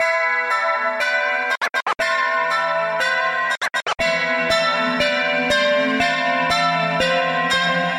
陷阱西塔循环
标签： 120 bpm Trap Loops Sitar Loops 1.35 MB wav Key : C
声道立体声